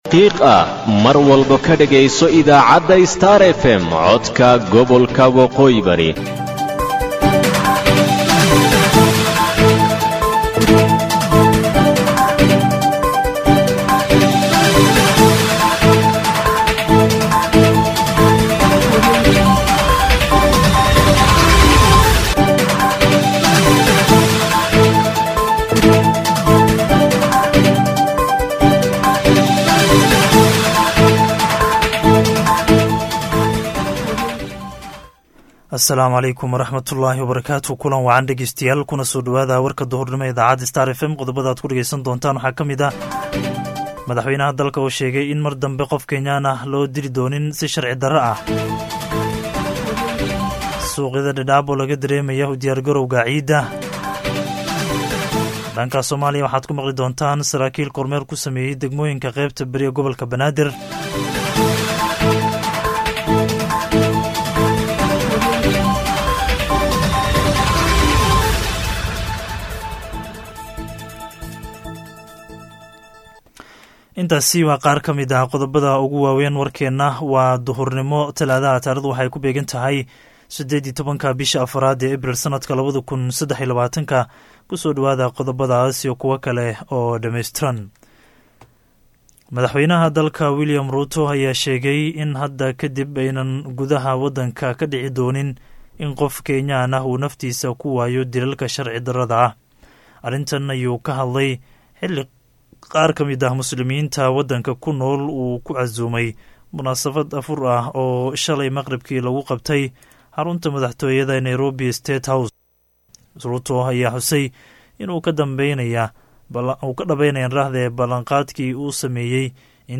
DHAGEYSO:WARKA DUHURNIMO EE IDAACADDA STAR FM